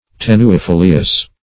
Search Result for " tenuifolious" : The Collaborative International Dictionary of English v.0.48: Tenuifolious \Ten`u*i*fo"li*ous\, a. [L. tenuis thin + folium a leaf.]
tenuifolious.mp3